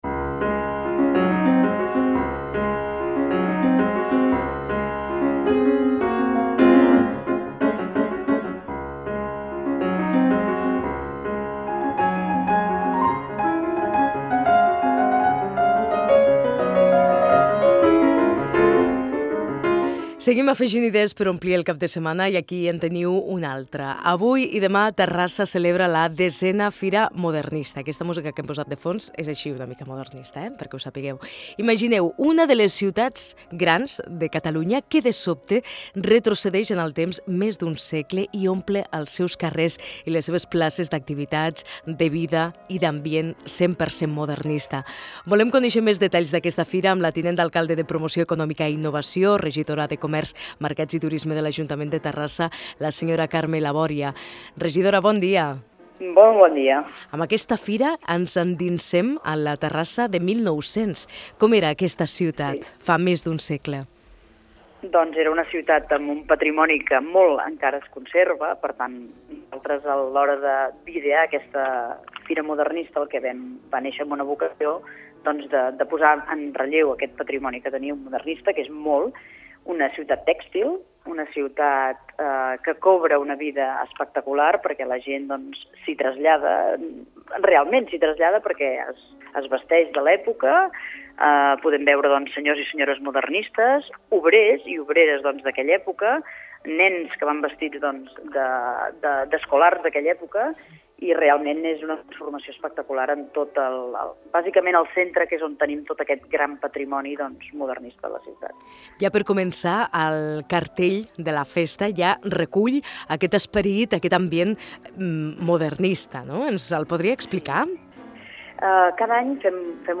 Fragment d'una entrevista a Carme Labòria, regidora de Terrassa, amb motiu de la 10èna Fira Modernista de Terrassa